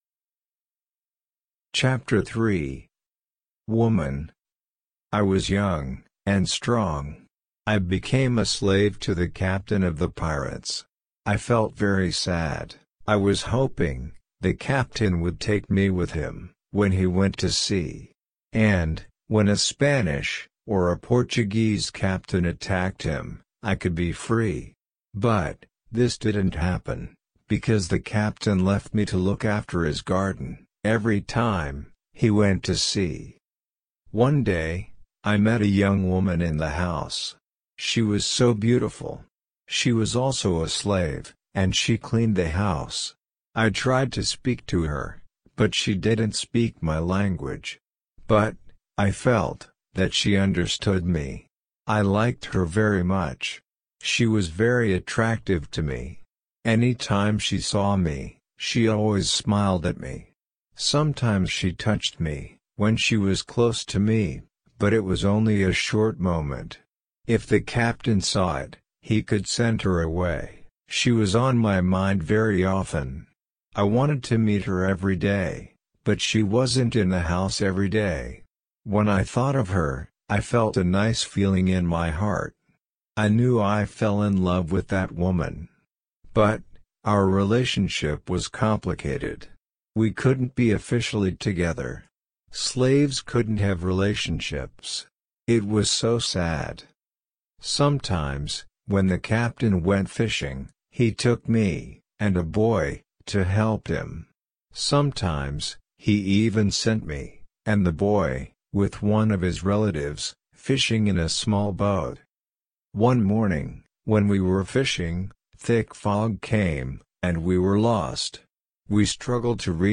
RC-L3-Ch3-slow.mp3